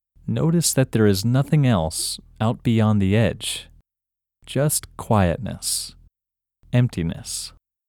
OUT – English Male 7